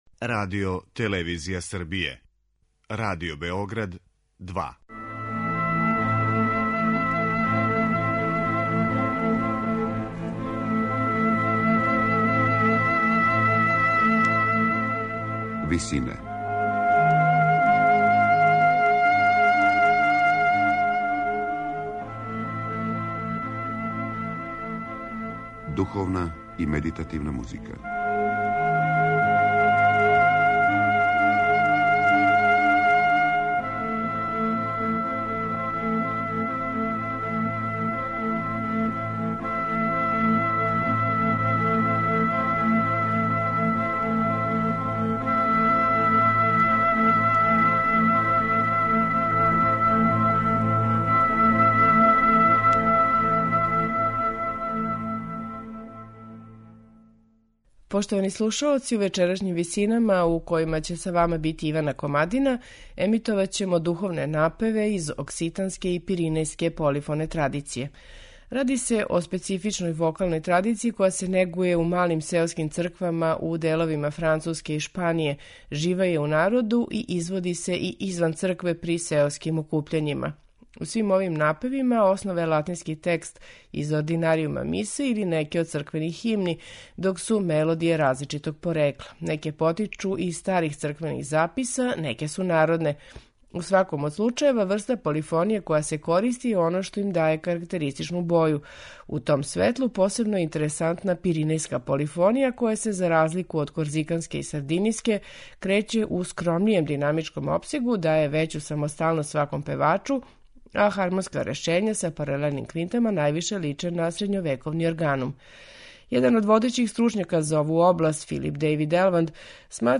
Духовни напеви из окситанске и пиринејске полифоне традиције
У сваком од случајева, врста полифоније која се користи је оно што им даје карактеристичну боју.
У вечерашњим Висинама, емитоваћемо духовне напеве из окситанске и пиринејске полифоне традиције, у интерпретацији вокалног квинтета Vox Bigeri.